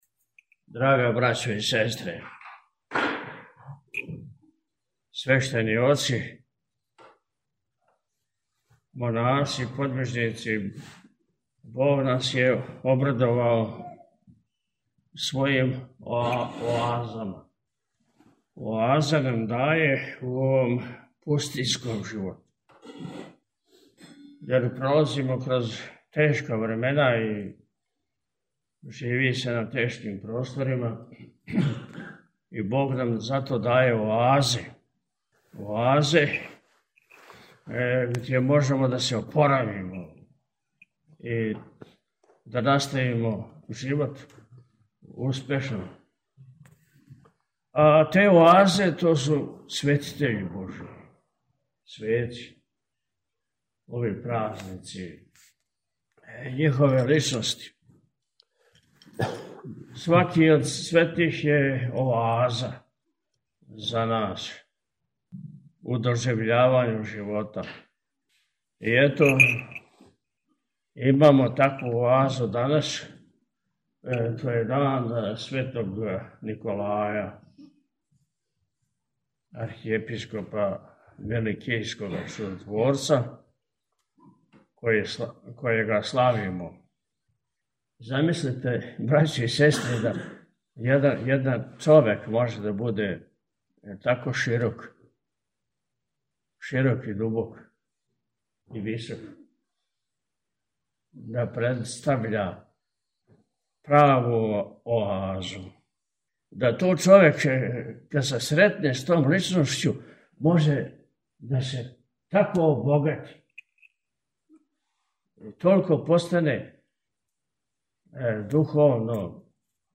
На дан када Православна Црква прославља дивног угодника Божијег, Светог Николаја Мирликијског Чудотворца, 19. децембра 2024. године, Његово Високопреосвештенство Архиепископ и Митрополит милешевски г. Атанасије служио је, поводом обележавања манастирске славе, Свету архијерејску Литургију у манастиру Светог Николаја у Бањи код Прибоја.
nikoljdan-vladika.mp3